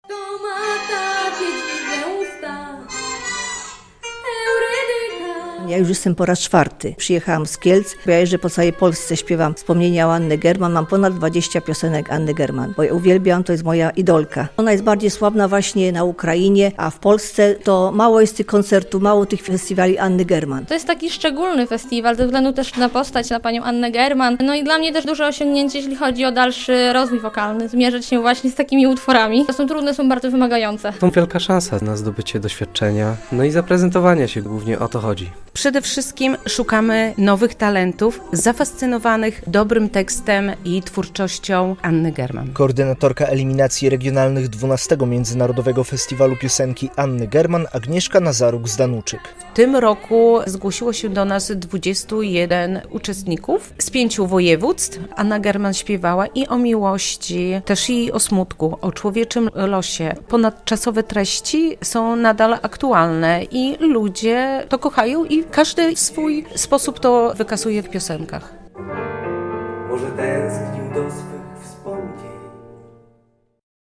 Blisko 20 osób w Bielsku Podlaskim wzięło udział w eliminacjach regionalnych XII Międzynarodowego Festiwalu Piosenki Anny German.
relacja